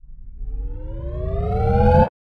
Charging_2S.wav